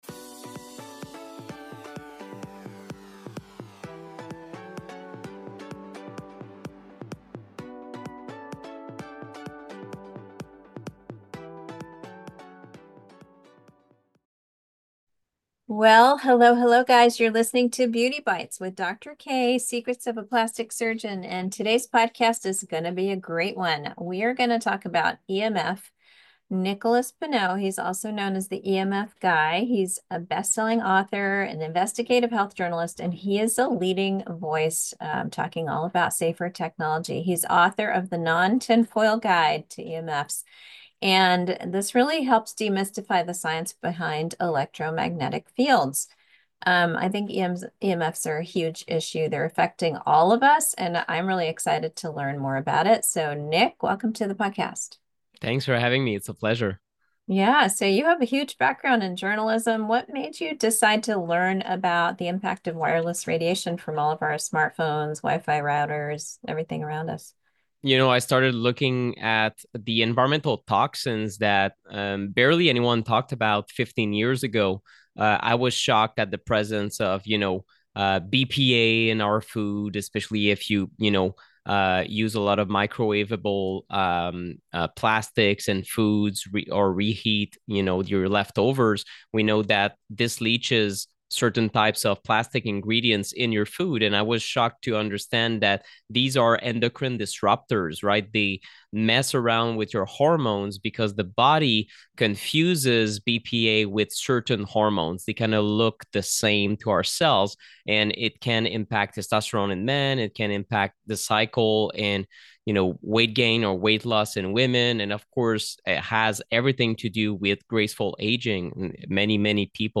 Alli sits down with me to share her personal journey as a businesswoman, mother, and partner. We tackle the pressures of being a woman, striving towards a healthy lifestyle through self-care, and prioritizing your mental health and well-being.